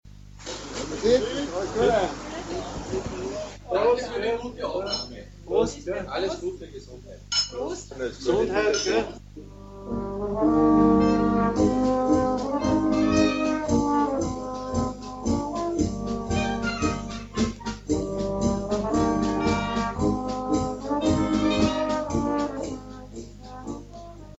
Hier befinden sich die MP3-Dateien, Mitschnitte aus der Radiosendung Aufhorchen in Niederösterreich vom Jänner 2008.
Glückwünsche und Marsch